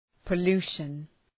Προφορά
{pə’lu:ʃən}